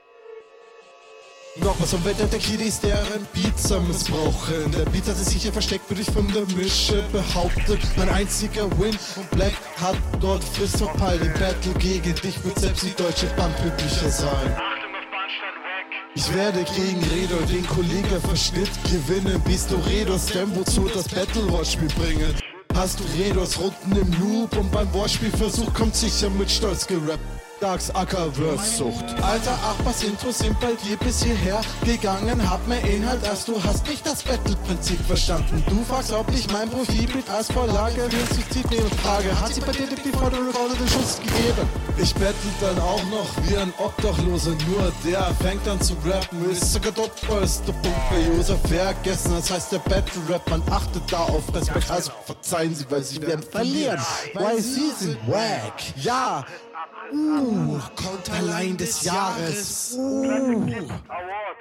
Dein Flow ist ein ständiger Wechsel zwischen onpoint und offpoint.